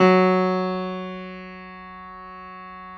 53h-pno08-F1.aif